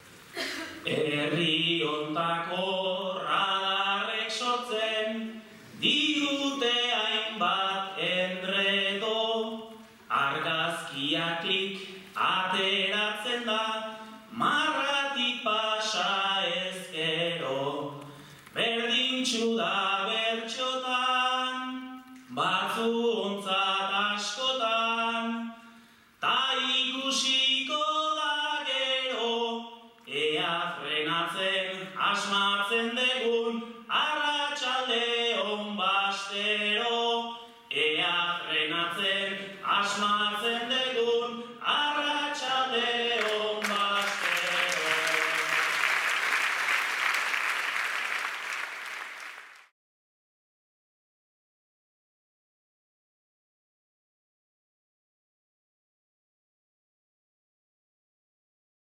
Gipuzkoako Bertsolari Txapelketa. 6. Final zortzirena